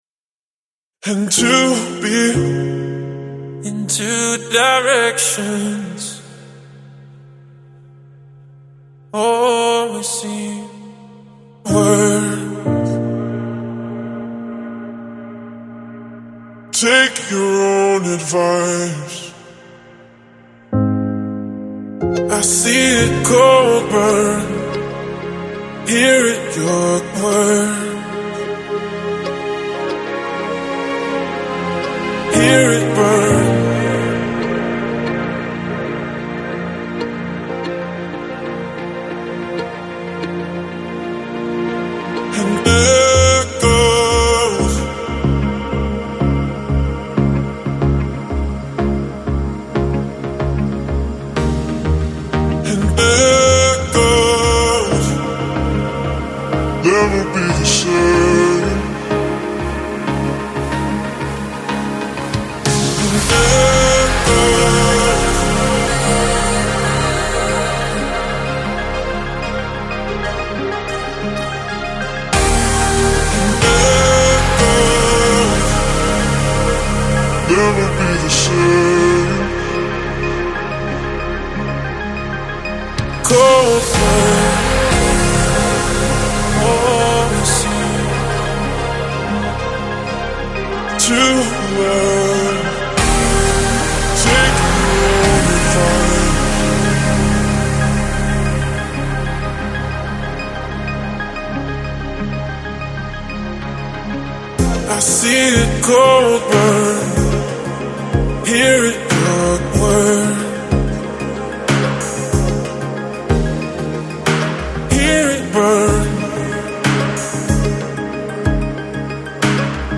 Electronic, Chill Out, Lounge, Downtempo Год издания